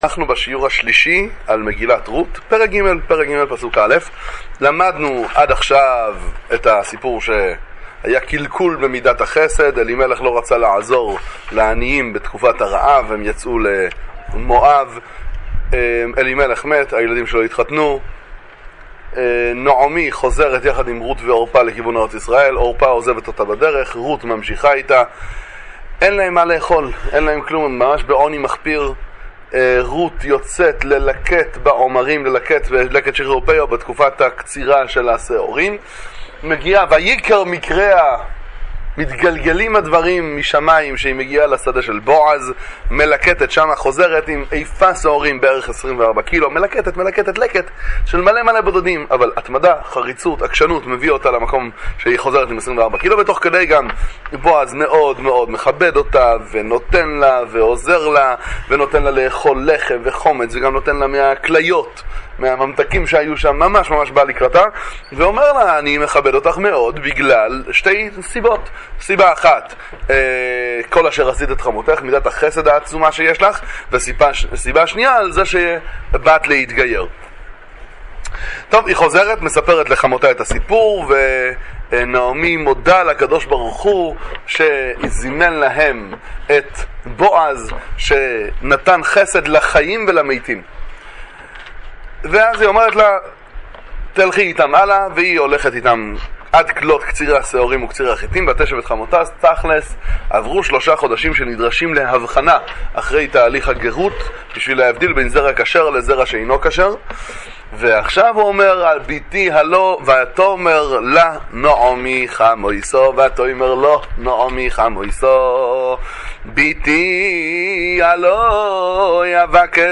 שיעור בנביאים וכתובים עם פירוש המלבי"ם, שיעורי תורה לחג השבועות, דברי תורה מגילת רות